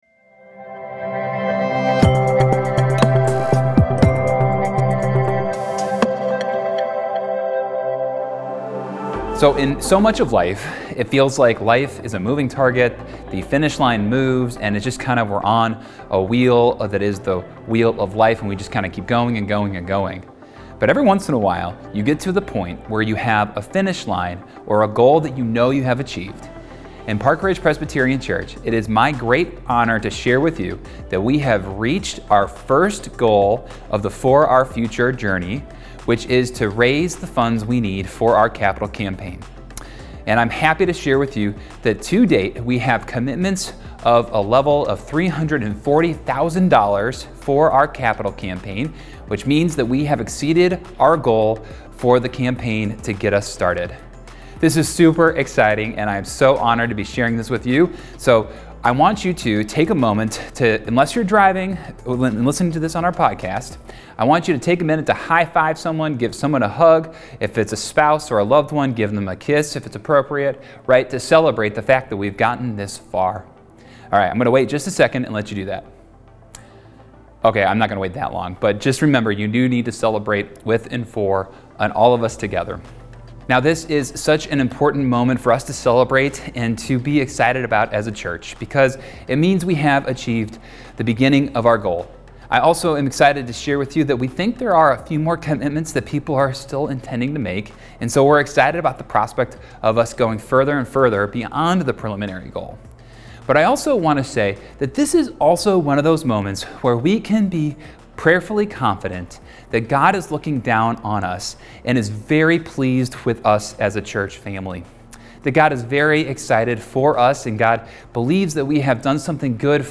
Sunday, November 17, 2024 – 10am Online Service – Week 6 of For Our Future